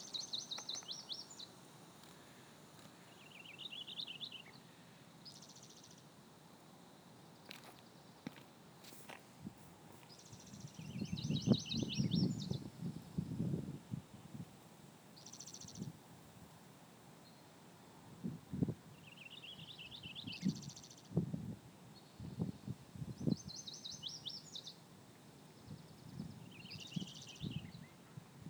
Swainson’s Thrush drip
Lazuli Bunting
Sibley Volcanic Regional Preserve